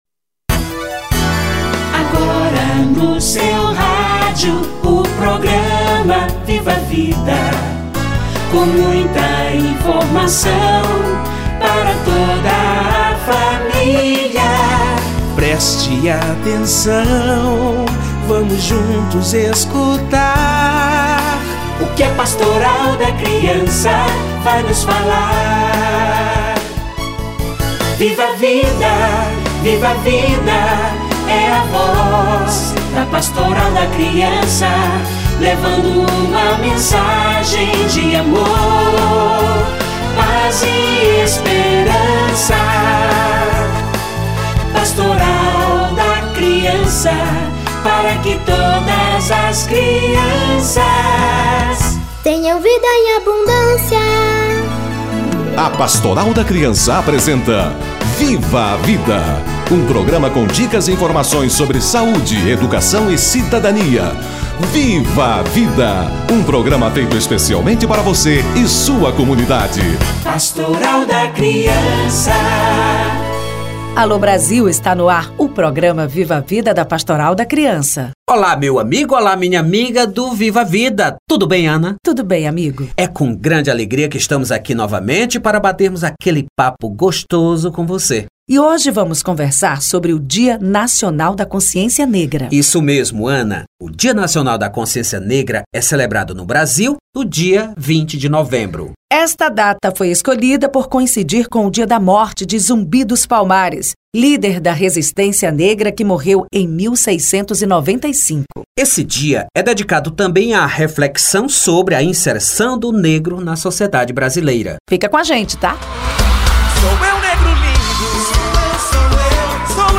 Dia da Consciência Negra - Entrevista